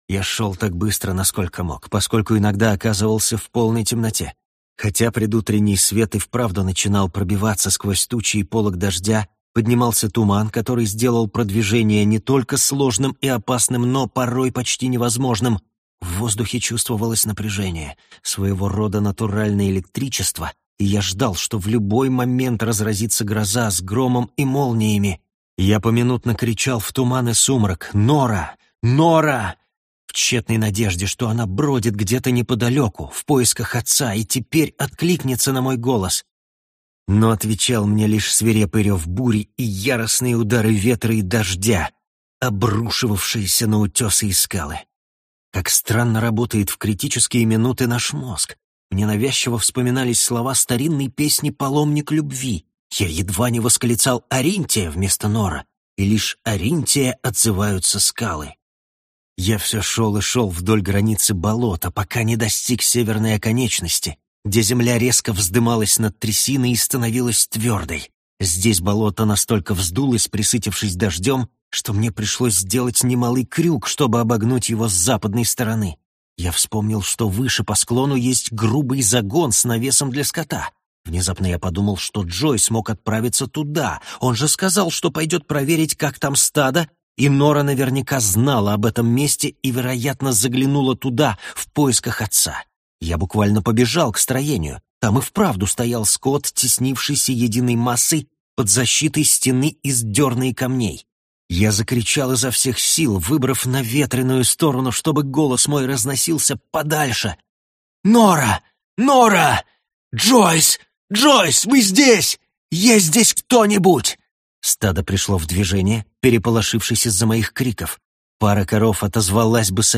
Аудиокнига Змеиный перевал | Библиотека аудиокниг